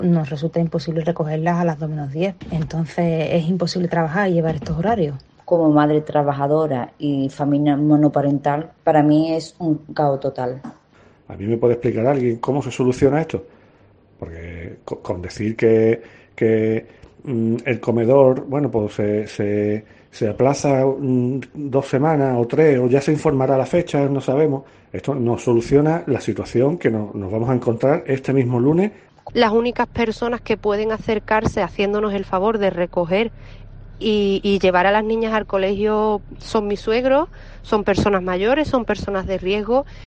Testimonio de padres sin comedor